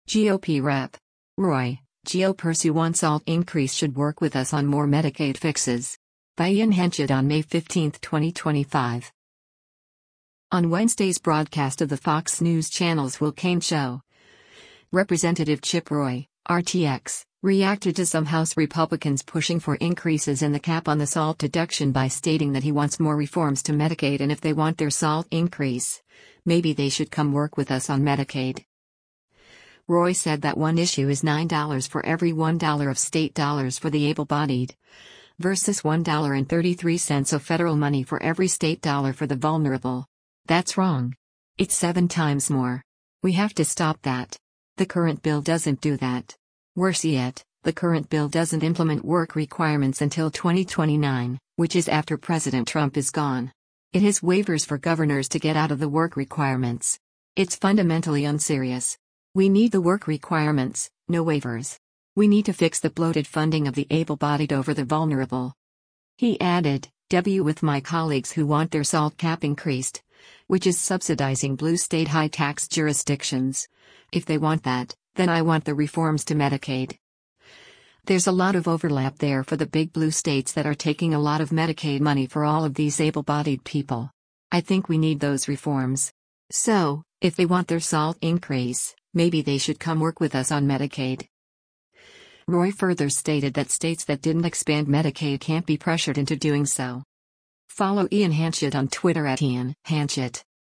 On Wednesday’s broadcast of the Fox News Channel’s “Will Cain Show,” Rep. Chip Roy (R-TX) reacted to some House Republicans pushing for increases in the cap on the SALT deduction by stating that he wants more reforms to Medicaid and “if they want their SALT increase, maybe they should come work with us on Medicaid.”